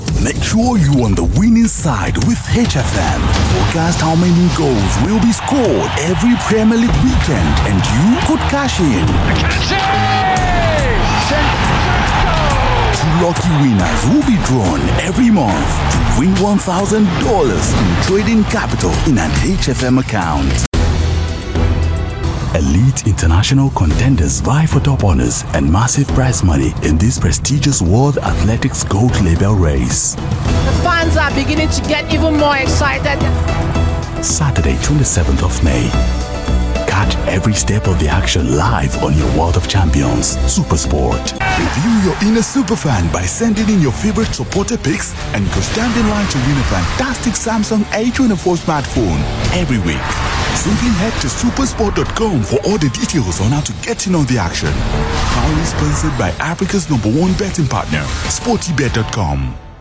a deep, warm baritone voice with authentic African and Nigerian accents
Promos
I specialize in recording voiceovers with authentic African accents, including West African and Sub saharan African accent.
Fully soundproofed booth
BaritoneBassDeep